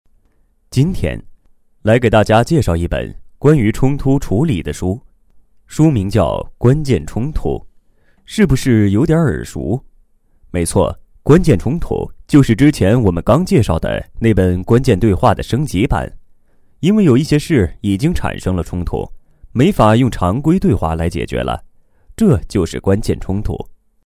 提供各类课件读物配音制作服务,如语音提示类,少儿读物,朗读朗诵,有声读物等。
《关键冲突》简介 自然叙述